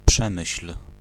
ملف تاريخ الملف وصلات معلومات الصورة (ميتا) Pl-Przemyśl.ogg  (Ogg Vorbis ملف صوت، الطول 1٫0ث، 117كيلوبيت لكل ثانية) وصف قصير Description Pl-Przemyśl.ogg English: Pronunciation of Przemyśl in Polish. Male voice.
Pl-Przemyśl.ogg.mp3